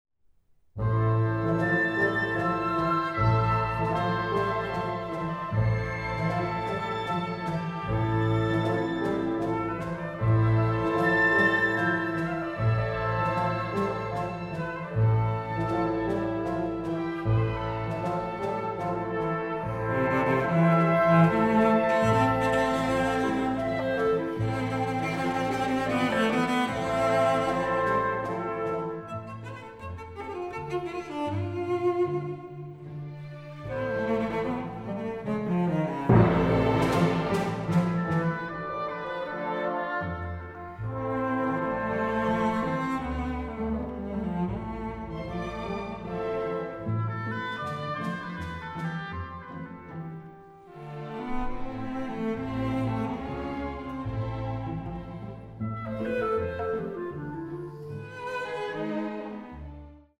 Aufnahme: Rehearsal Hall, Megaron, Athens, 2024